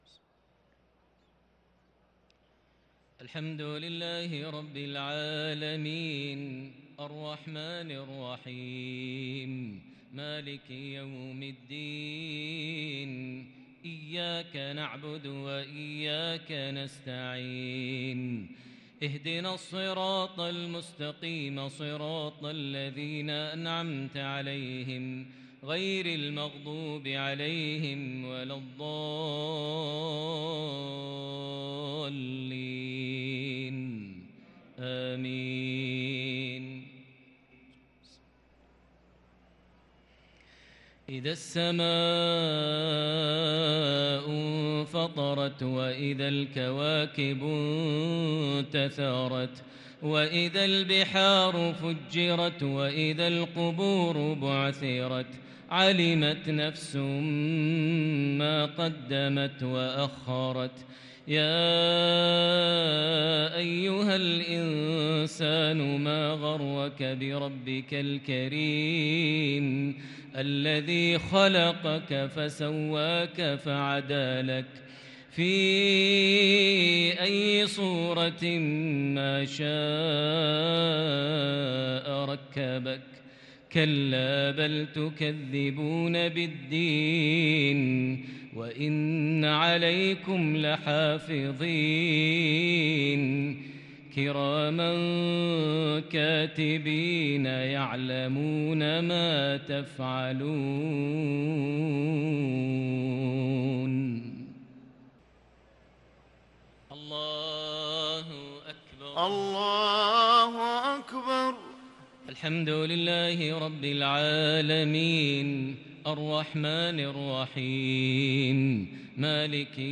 صلاة العشاء للقارئ ماهر المعيقلي 13 جمادي الأول 1444 هـ
تِلَاوَات الْحَرَمَيْن .